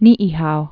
(nēē-hou)